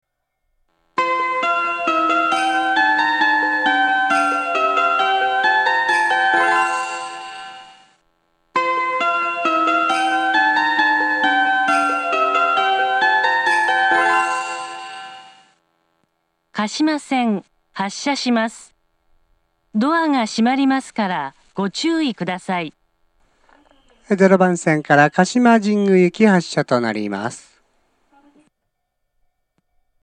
列車の本数が少ないので駅員放送が入ることが多く、メロディー・放送に被ることも多いです。
０番線鹿島線発車メロディー 曲は「古いオルゴール」です。